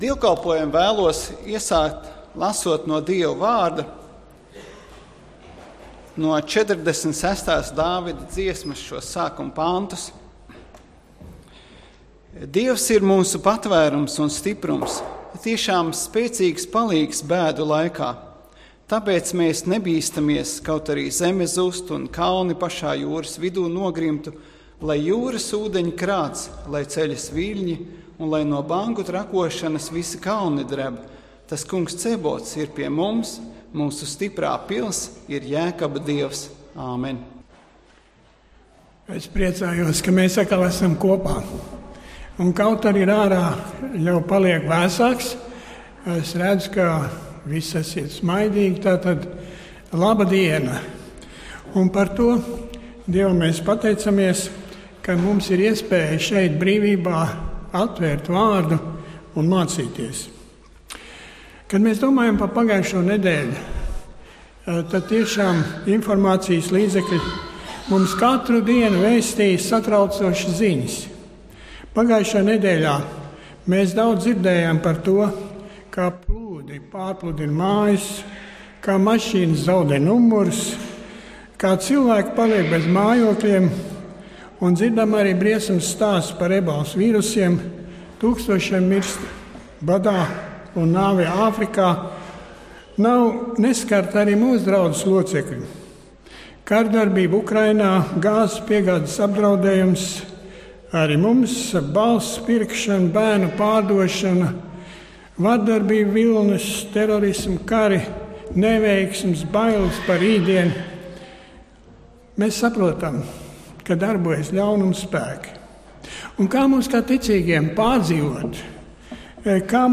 Dievkalpojums 18.10.2014: Klausīties
Svētrunas